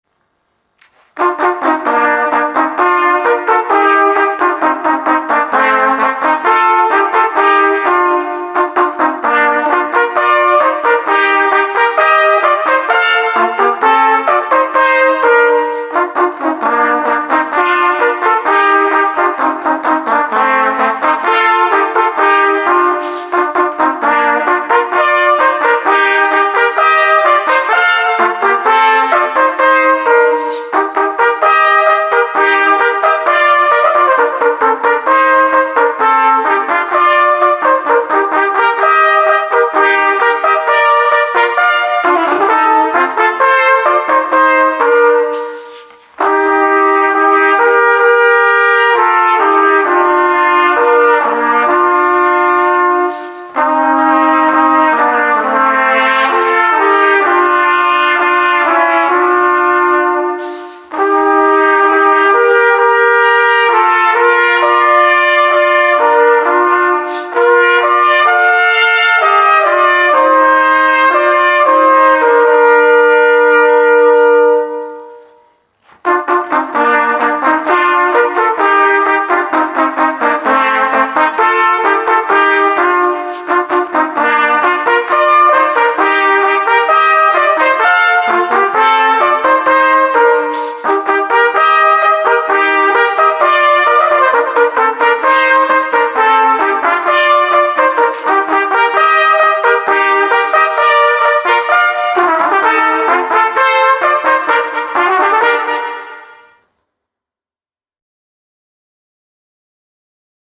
Gattung: für zwei Trompeten oder Flügelhörner